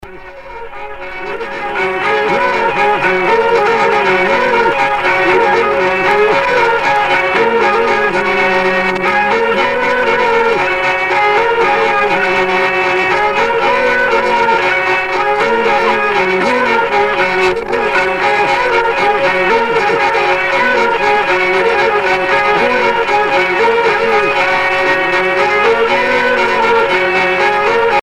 danse : aéroplane
Sonneurs de vielle traditionnels en Bretagne
Pièce musicale éditée